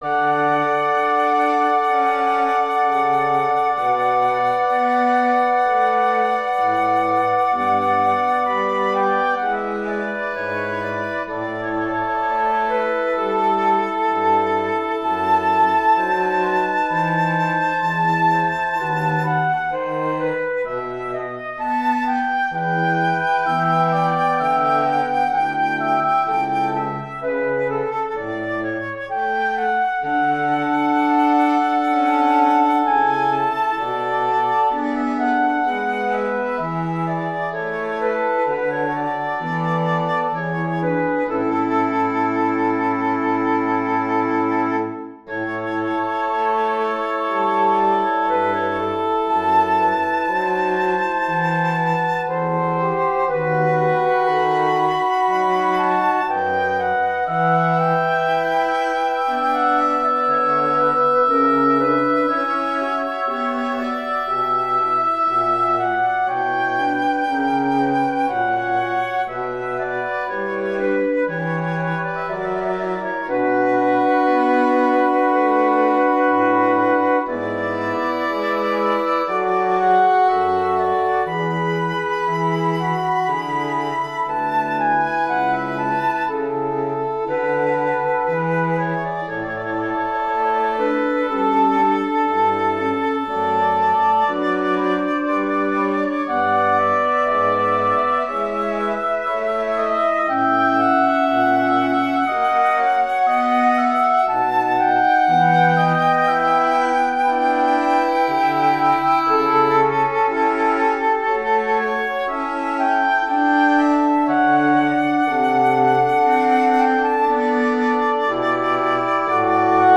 Voicing: Flex Ensemble